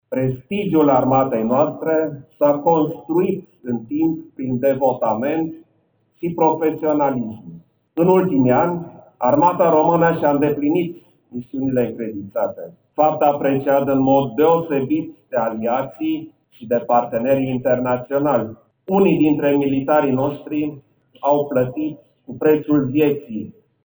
La ceremonii a participat și preşedintele Klaus Iohannis, care a depus astăzi coroane de flori la monumentele eroilor din Păuliş şi municipiul Arad.
Cu acest prilej, șeful statului a vorbit despre importanţa şi prestigiul armatei.